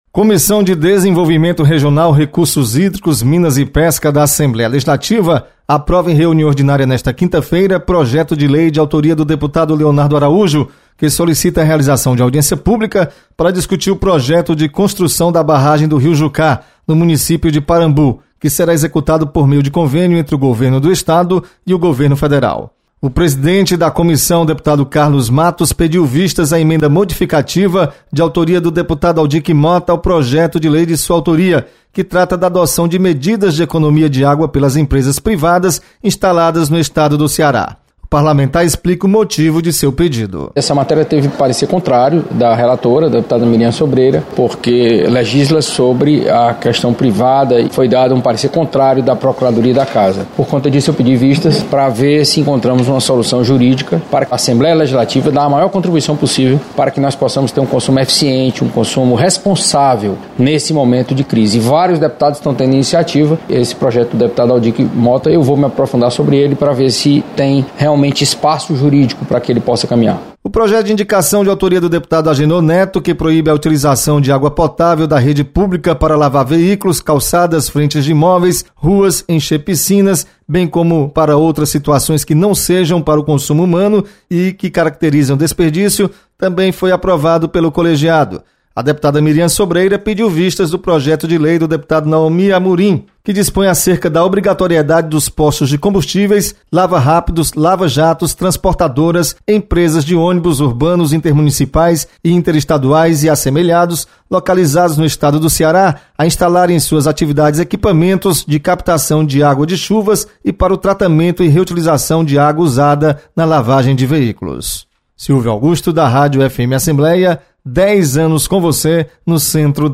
Comissão de Desenvolvimento Regional, Recursos Hídricos, Minas e Pesca realiza reunião nesta quinta-feira. Repórter